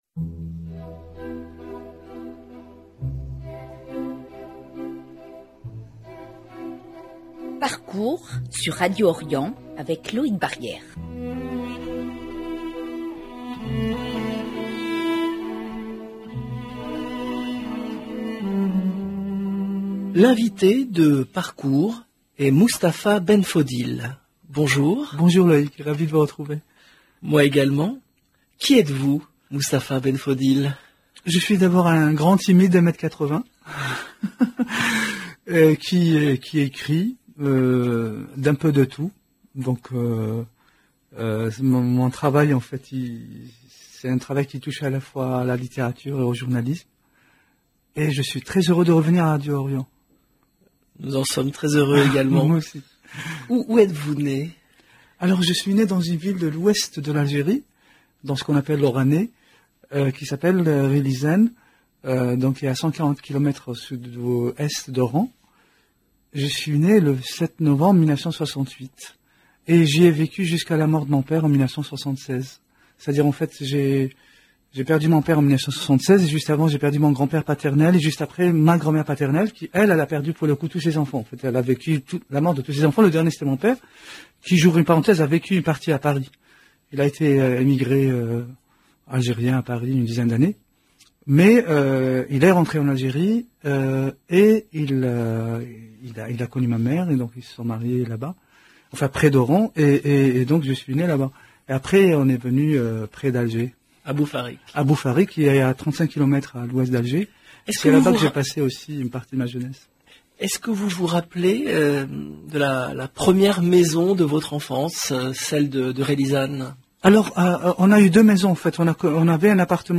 L'écrivain Mustapha Benfodil invité de Parcours